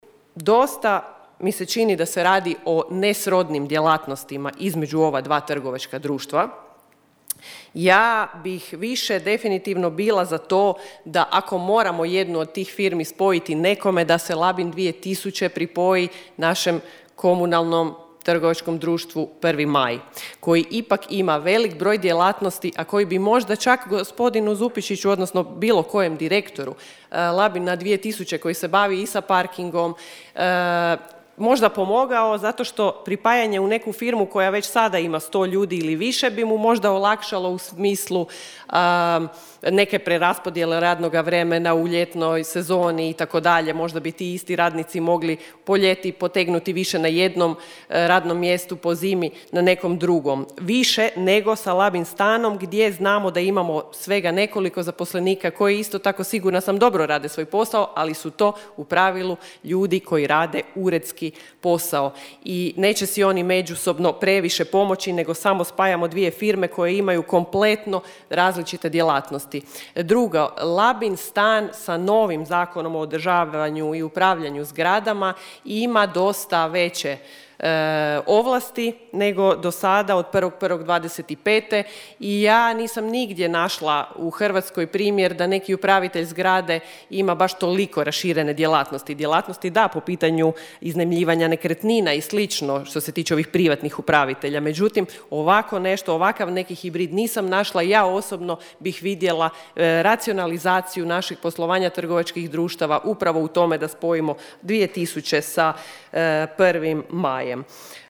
S takvom odlukom nije se baš složila vijećnica IDS-a Federika Mohorović Čekada rekavši kako podržava svaku racionalizaciju poslovanja, međutim: (
ton – Federika Mohorović Čekada).